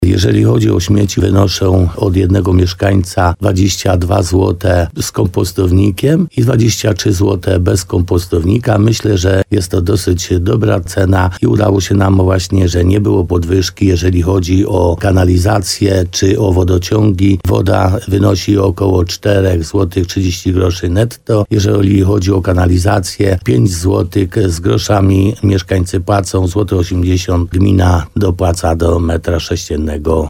To między innymi z tego powodu udało się uniknąć zapowiadanych wcześniej podwyżek – mówił w programie Słowo za Słowo w radiu RDN Nowy Sącz mówił wójt Piotr Stach.